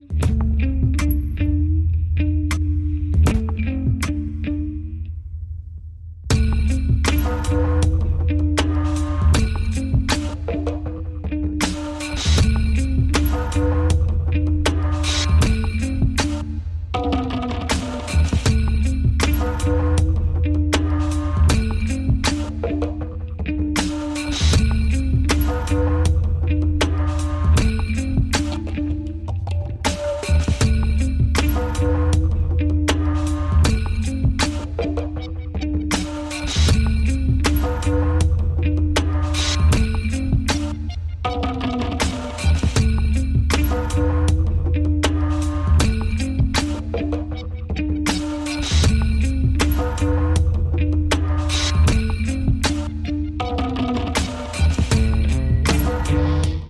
атмосферные
саундтреки
спокойные
без слов
релакс
инструментальные
медленные